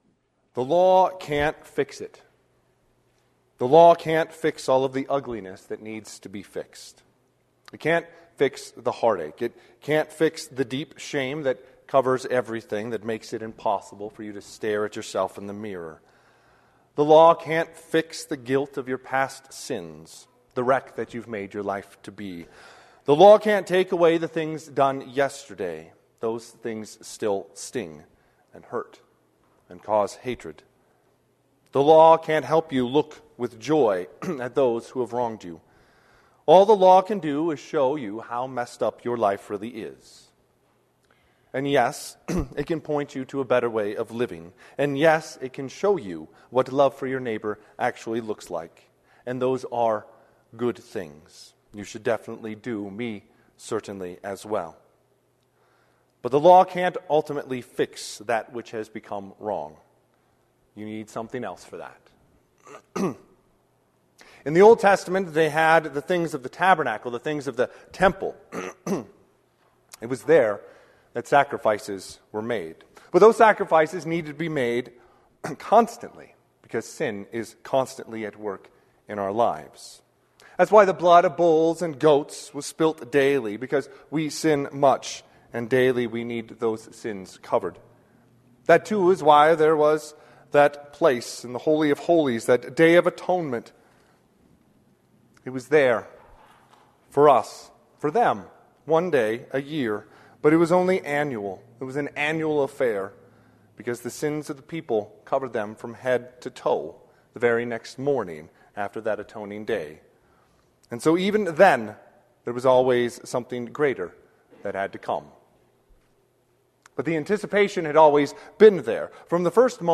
Sermon - 12/18/2024 - Wheat Ridge Evangelical Lutheran Church, Wheat Ridge, Colorado
Advent Service, Evening Prayers